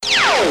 mininglaser.wav